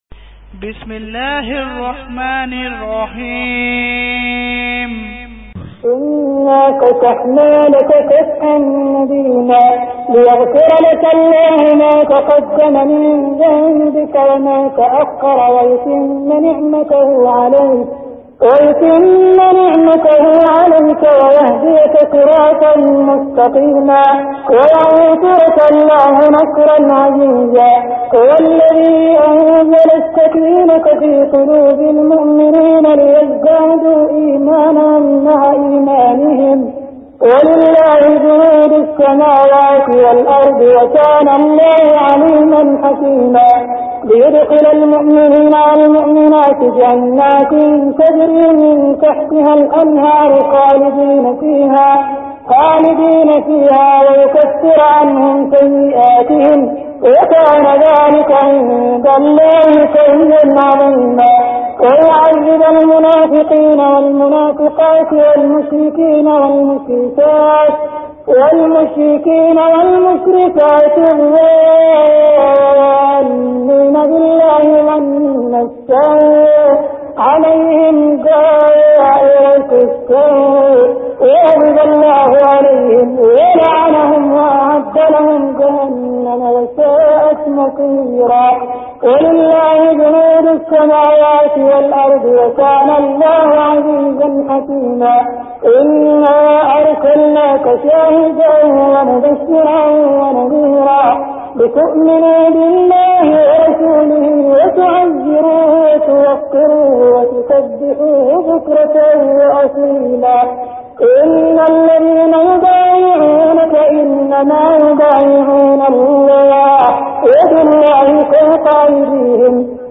Surah Al Fath Beautiful Recitation MP3 Download By Abdul Rahman Al Sudais in best audio quality.